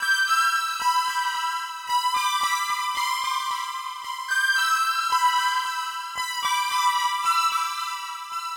Index of /DESN275/loops/Loop Set - Spring - New Age Ambient Loops/Loops
Syrup_112_C_SynthMelody.wav